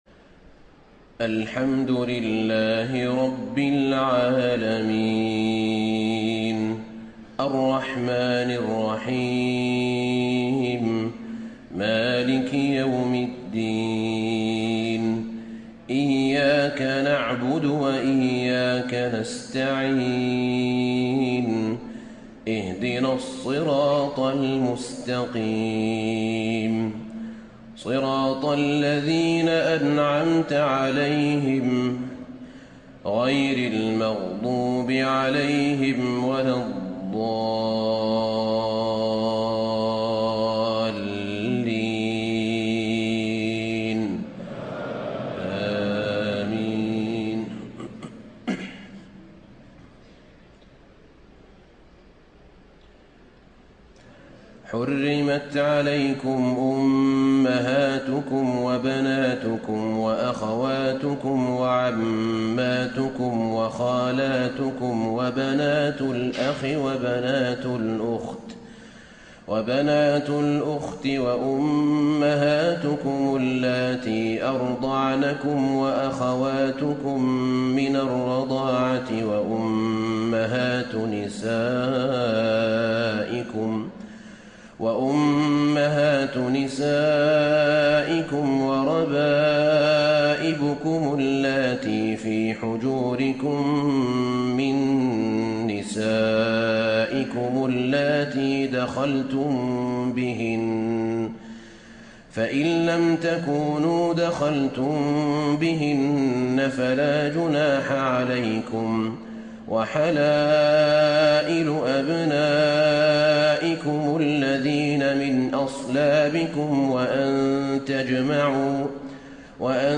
تهجد ليلة 25 رمضان 1434هـ من سورة النساء (23-100) Tahajjud 25 st night Ramadan 1434H from Surah An-Nisaa > تراويح الحرم النبوي عام 1434 🕌 > التراويح - تلاوات الحرمين